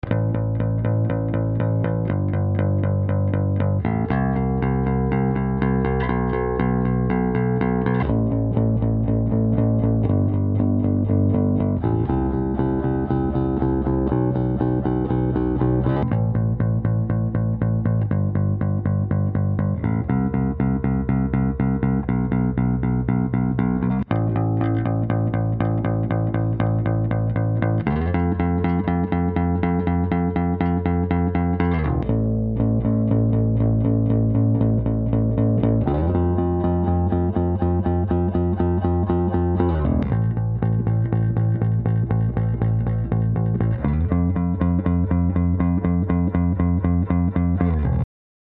Pro srovnání jak se nechá Aria drbat trsátkem
Basy se střídaj vždy po 4 taktech,nastavení jednotně bez korekcí na krkovej snímač.
Aria Pro II TSB 650 - Peavey T40 - ESP JB - Aria Pro II TSB 650 - Peavey T40 - ESP JB